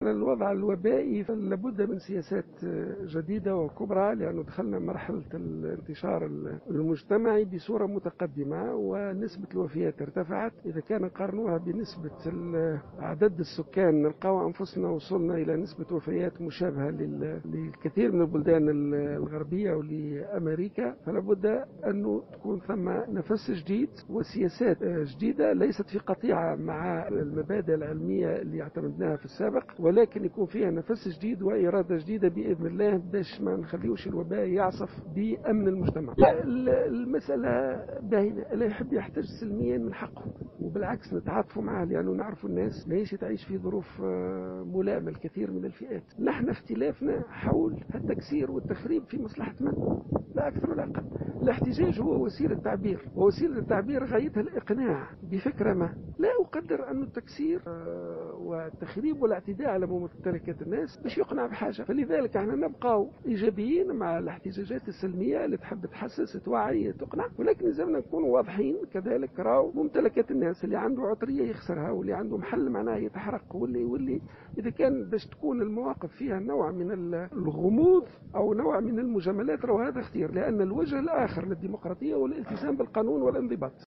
وقال في تصريح لـ "الجوهرة أف أم" بمناسبة وصوله الى قرمبالية لحضورة موكب جنازة الراحلة محرزية العبيدي، اننا نجد انفسنا امام تسجيل نسب وفيات قريبة مما تم تسجيله في كثير من البلدان الغربية و الولايات المتحدة الامريكية.